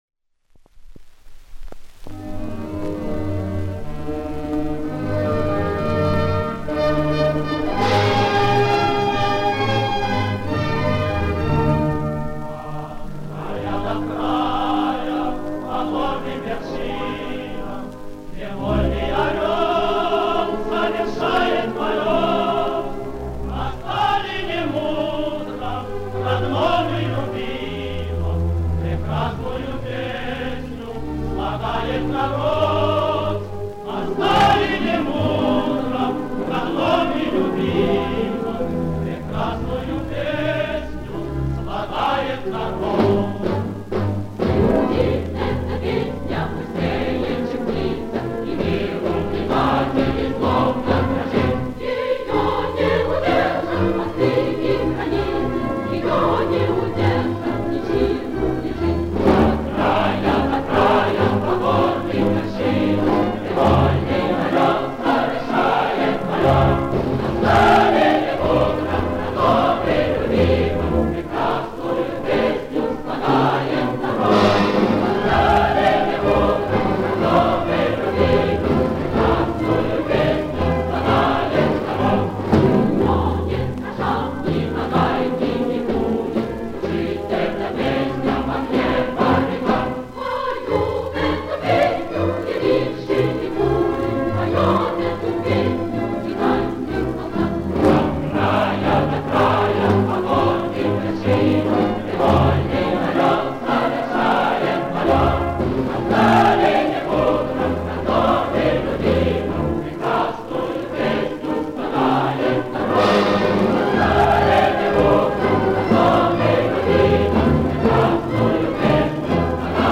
Величественная ,торжественная песня